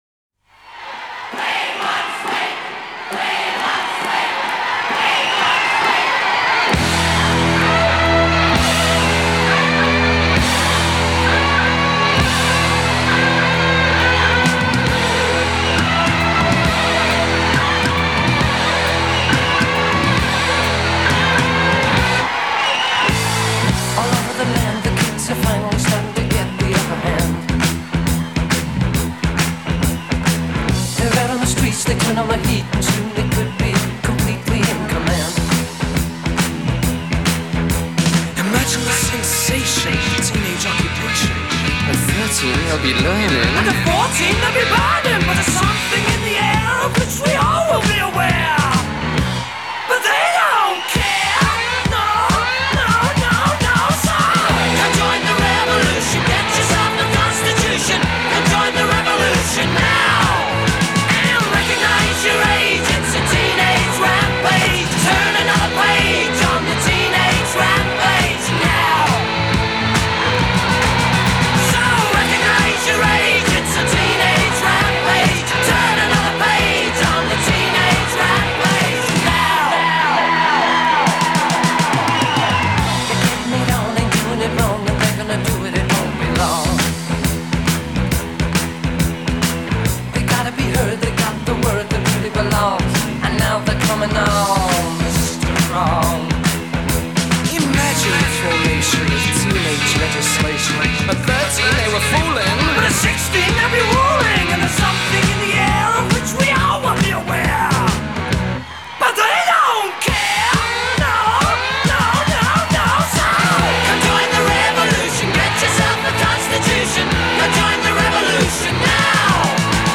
Genre: Glam Rock, Hard Rock